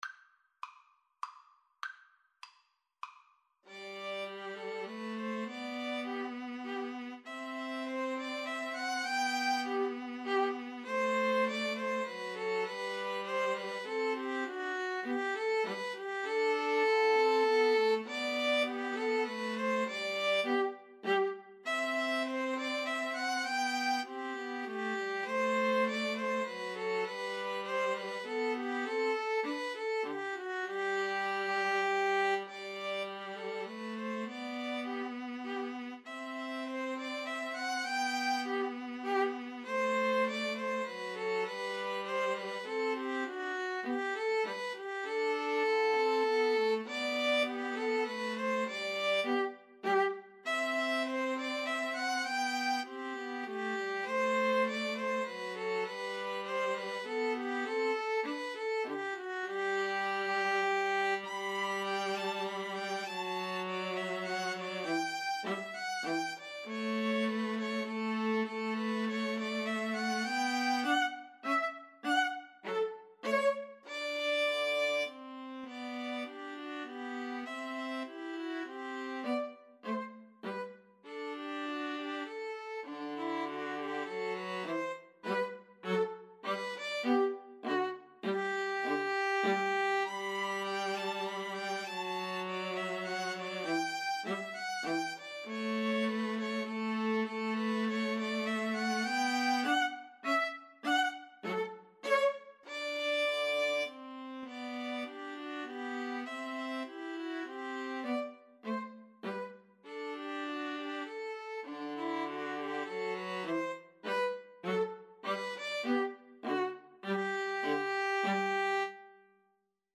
Classical (View more Classical 2-violins-viola Music)